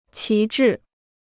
旗帜 (qí zhì): ensign; flag.